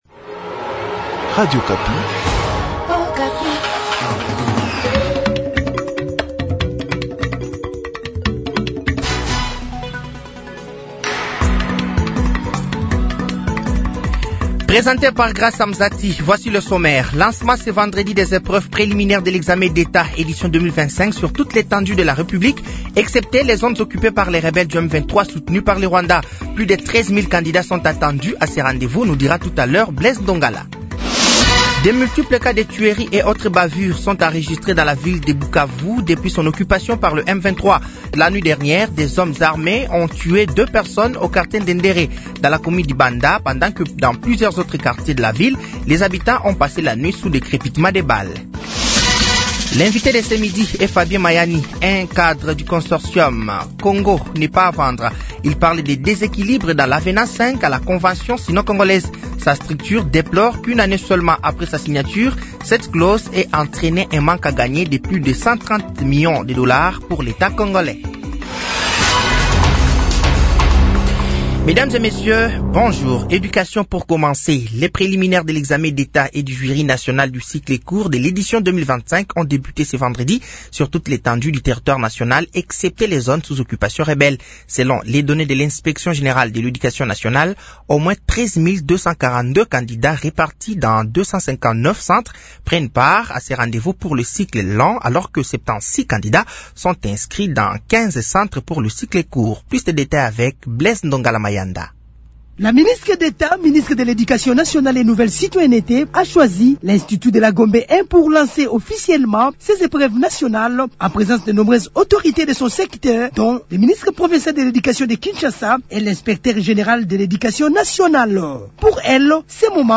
Journal français 12h de ce vendredi 07 mars 2025